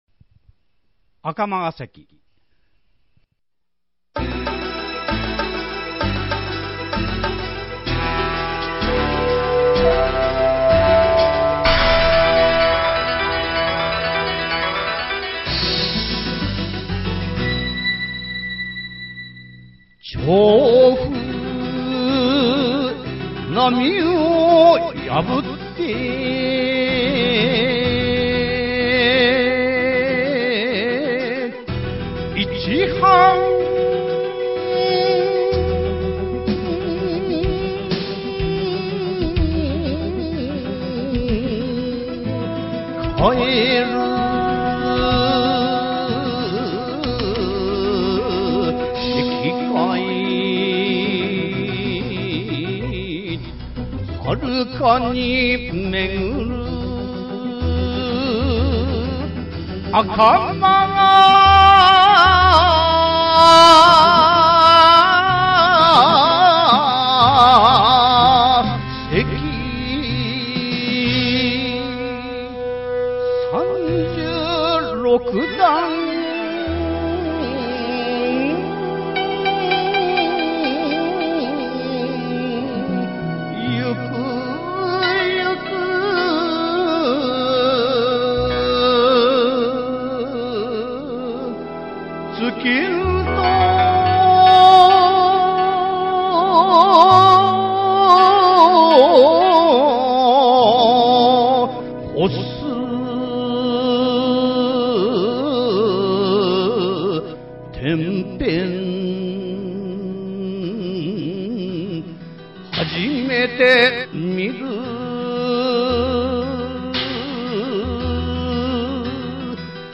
平起こり七言絶句の形であって、上平声十五刪（さん）韻の還、関、山の字が使われている。